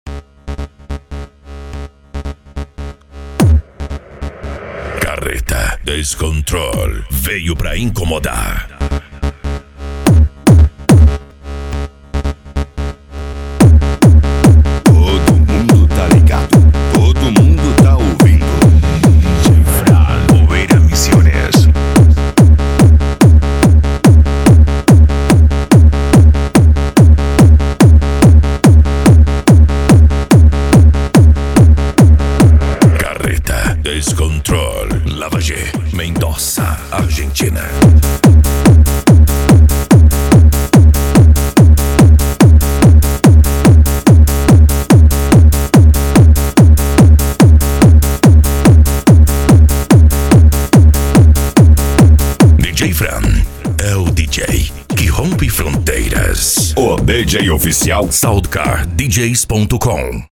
Bass
PANCADÃO
Remix